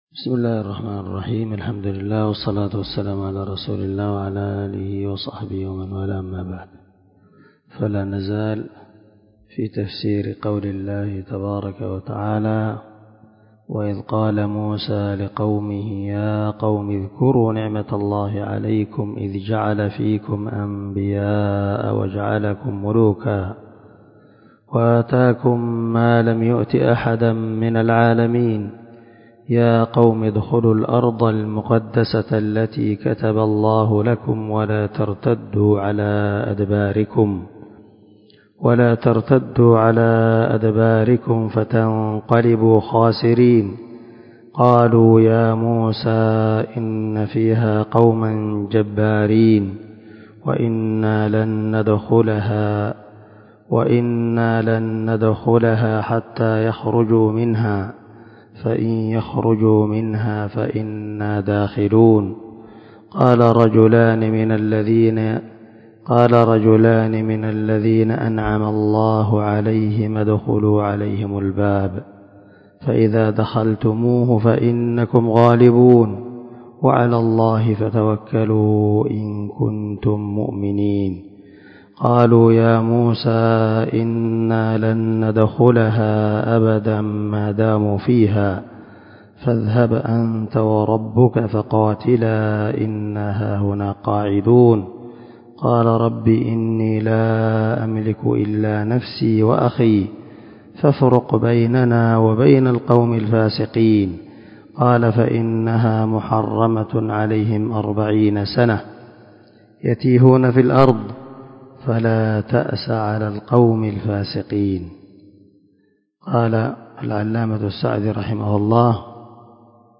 353الدرس 20 تابع تفسير آية ( 20 – 26 ) من سورة المائدة من تفسير القران الكريم مع قراءة لتفسير السعدي